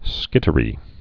(skĭtə-rē)